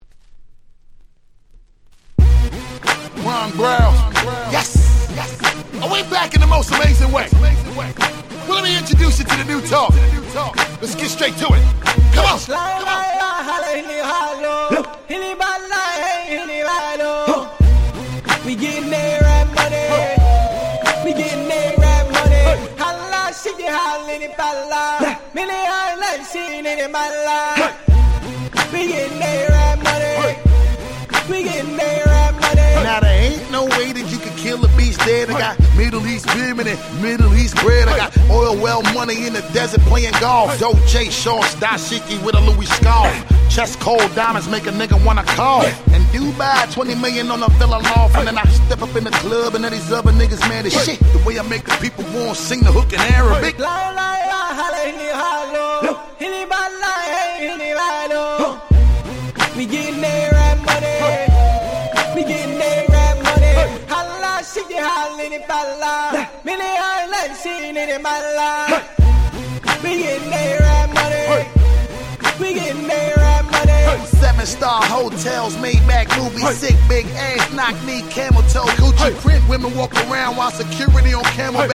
08' Super Hit Hip Hop !!